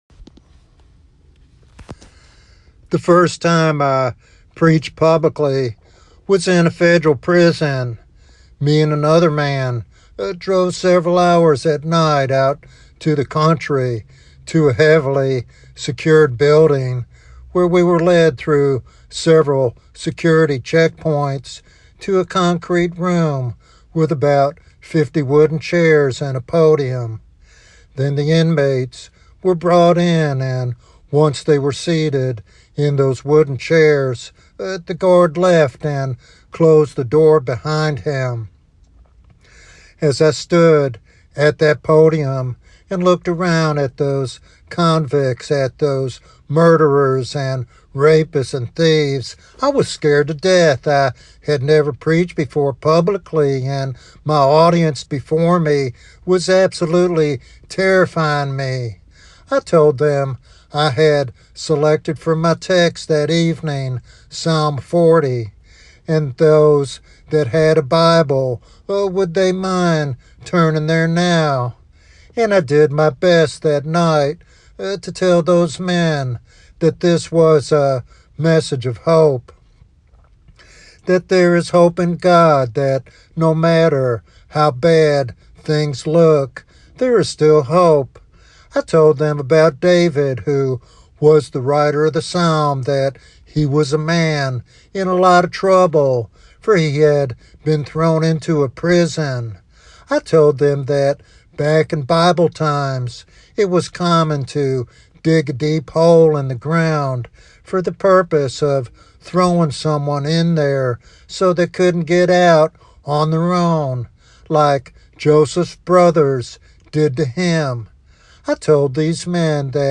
In this heartfelt sermon